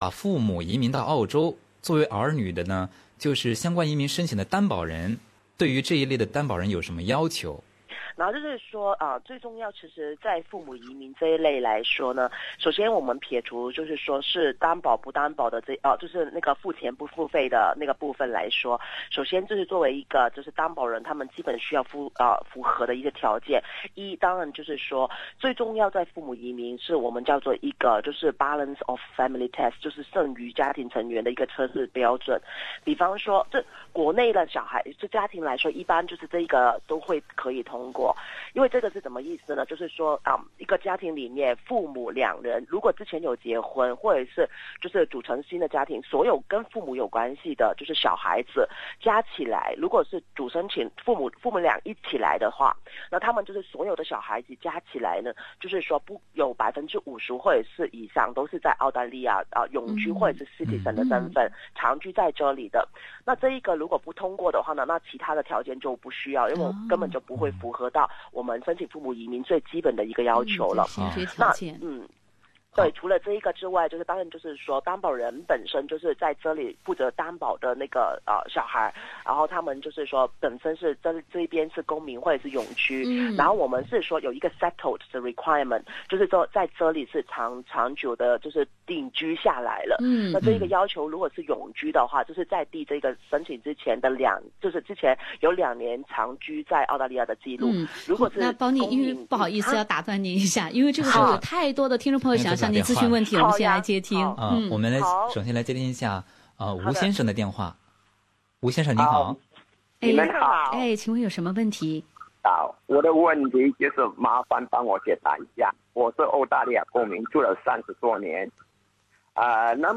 您还将听到参与现场直播节目的听众所提问的一些问题。